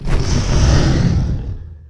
attack1.wav